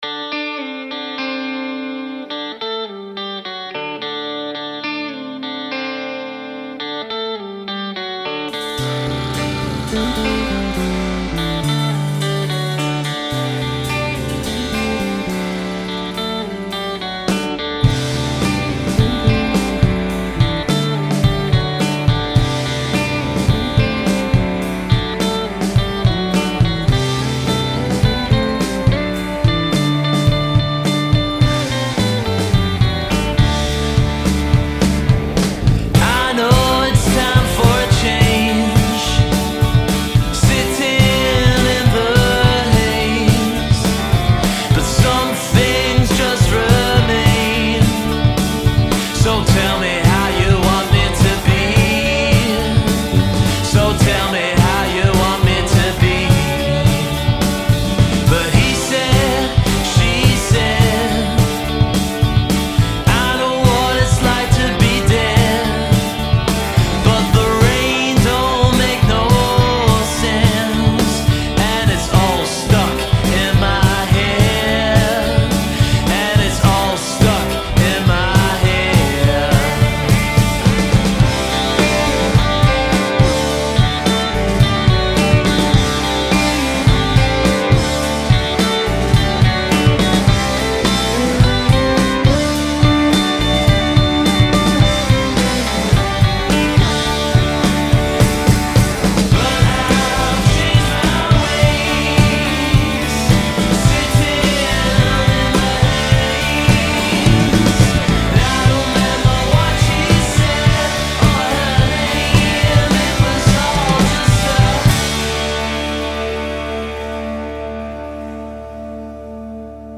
modern psychedelic rock
Rock